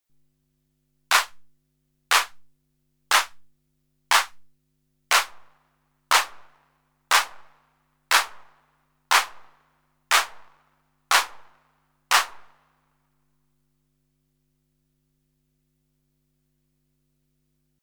Other than that, I've noticed a couple of weird things - i seems that the reverb part of my clap does not sound for the first couple of times:
Then if I leave it a while without playing, the reverb is gone again, until a few claps have sounded.
Yocto_Clap_Later_Reverb.mp3